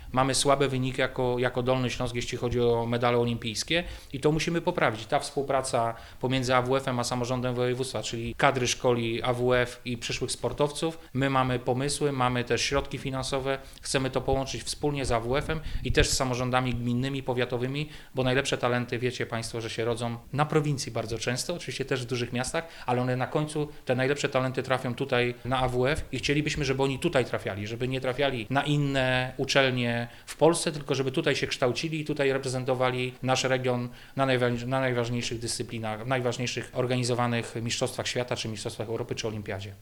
Wojciech Bochnak nie ukrywa, że chciałby, aby dolnośląscy sportowcy przywozili więcej medali z Igrzysk Olimpijskich, a także dodaje jak można to zmienić.